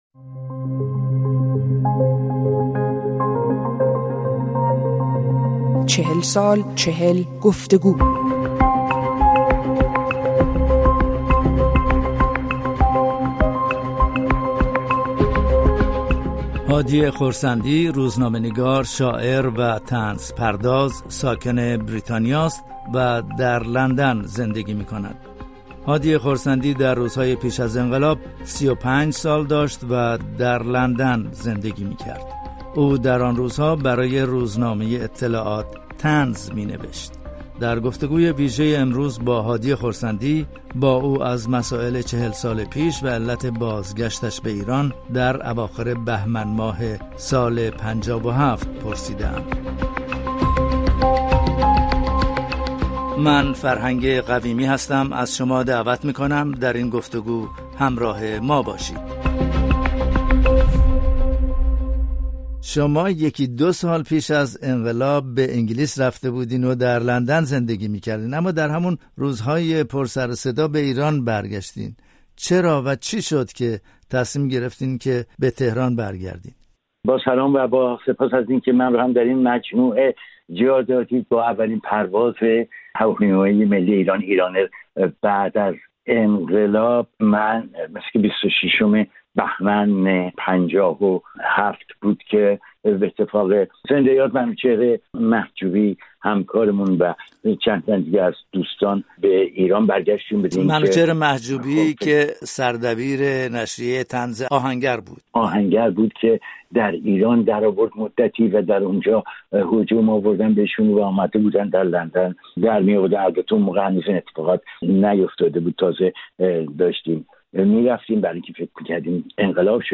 در چهلمین سالگرد انقلاب بهمن ۵۷، در قالب چهل گفت‌وگو با چهره‌های تاریخ‌ساز یا فعال ایرانی در آن سال‌ها، روایت آنها را از این رویداد و تأثیرات آن، و نیز چشم‌انداز آنها از آینده ایران بازجسته‌ایم. در این برنامه‌ به سراغ هادی خرسندی رفته‌ایم؛ روزنامه‌نگار، شاعر و طنزپرداز ساکن بریتانیا.